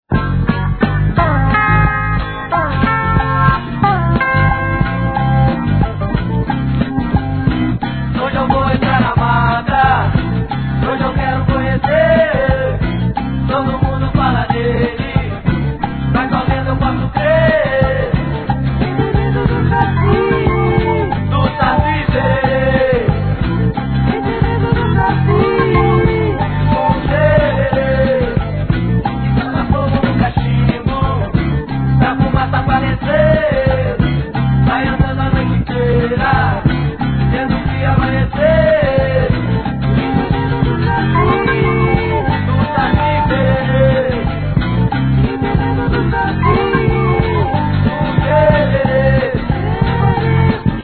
店舗 ただいま品切れ中です お気に入りに追加 ブラジルのオルガン奏者!スウィンギン･ブラジリアン･ジャズ・グルーヴ！